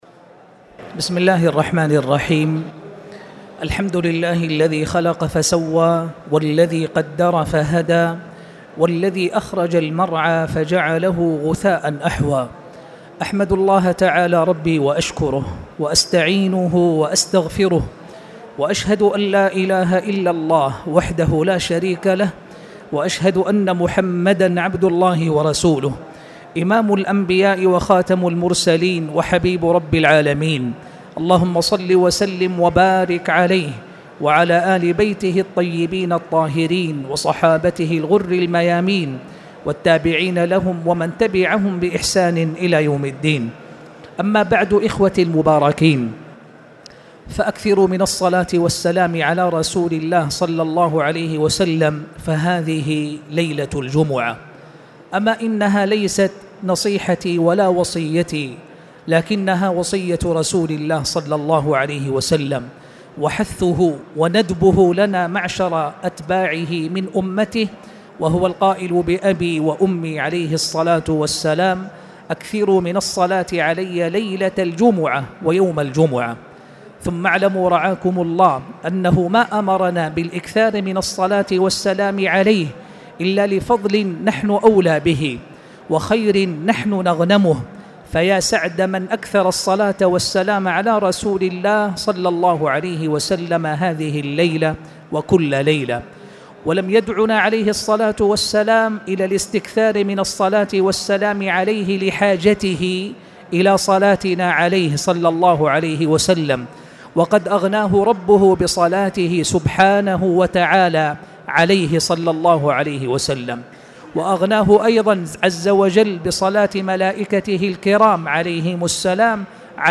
تاريخ النشر ٢٢ شعبان ١٤٣٨ هـ المكان: المسجد الحرام الشيخ